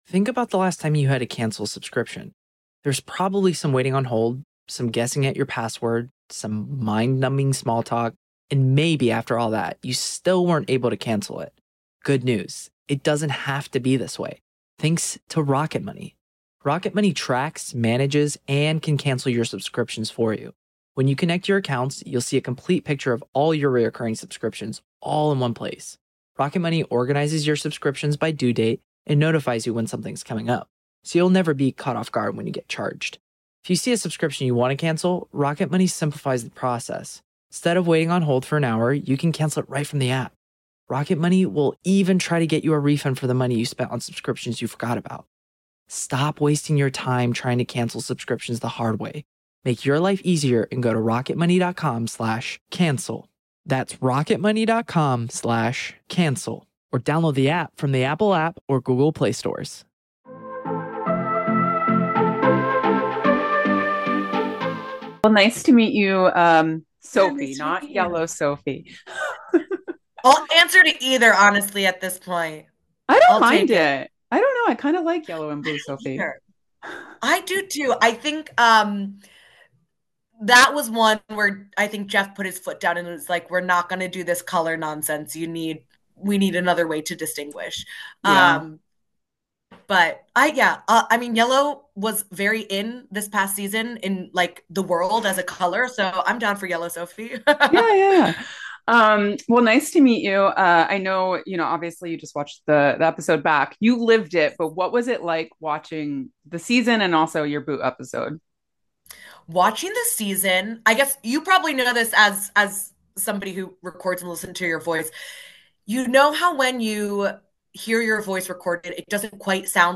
Survivor 49 Exit Interview: 11th Player Voted Out Speaks!